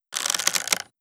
FueraDeEscala/Assets/Game Kit Controller/Sounds/Weapons/Reload/Load Arrow.wav at 1d9c52a2b2f55e000b66a7ce3ebfaa0ed95f90c7
Load Arrow.wav